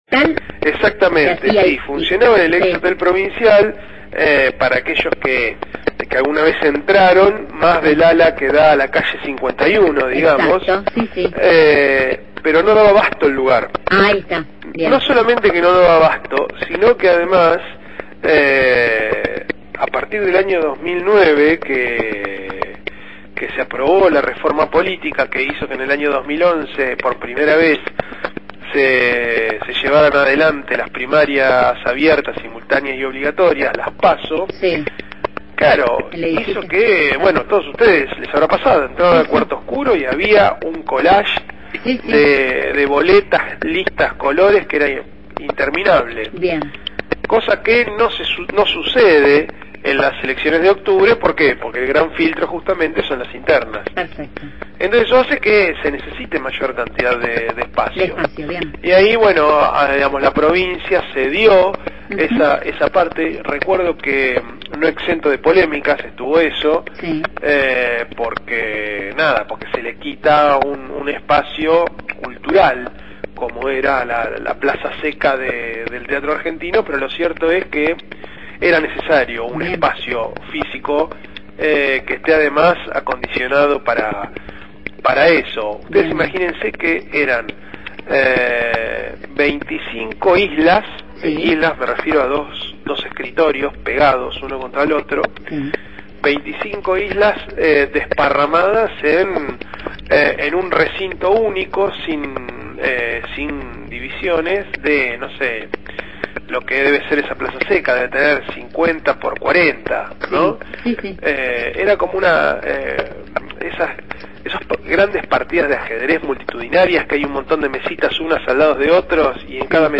Columna jurídica